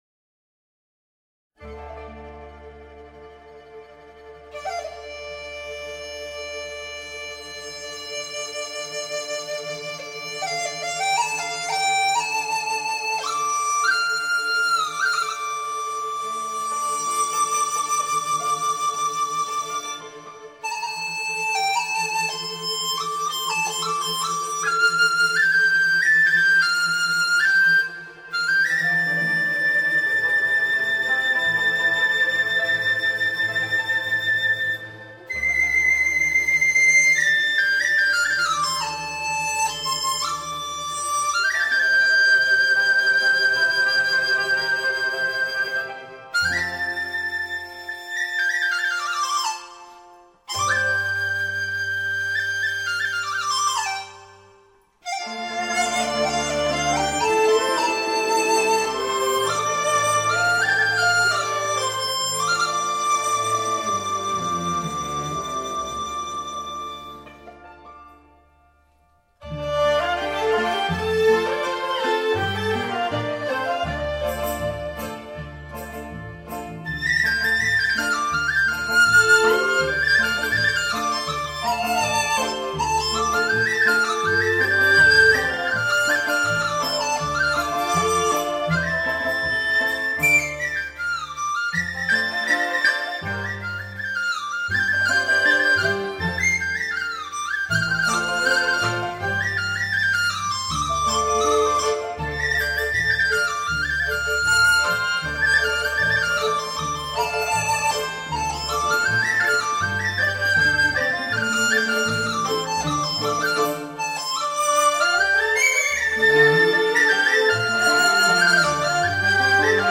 (梆笛)